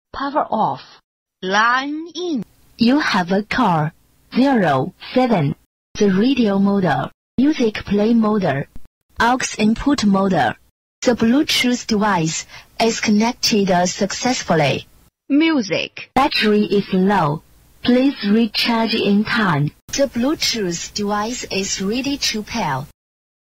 Chinese bluetooth power off